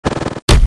合成牌音效.MP3